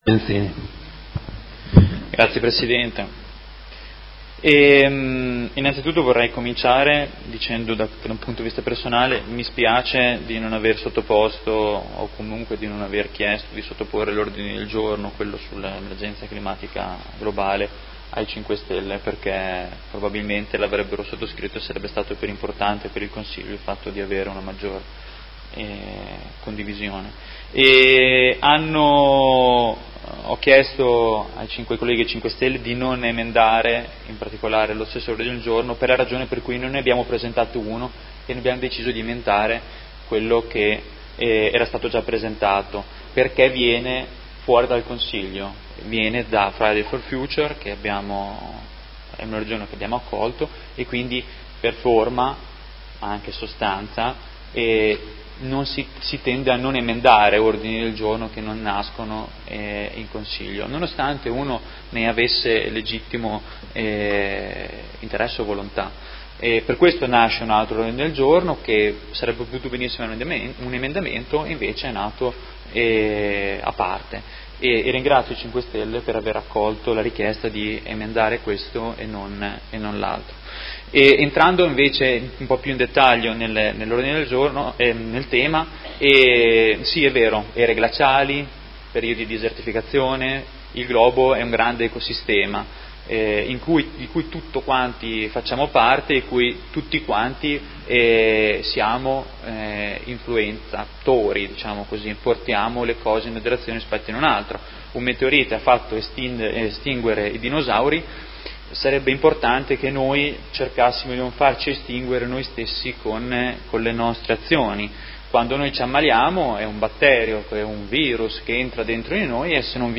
Seduta del 25/07/2019 Dibattito. Mozione nr. 187936 - Mozione nr. 221209 ed emendamenti